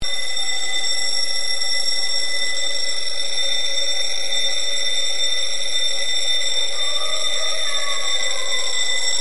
Звуки школьной перемены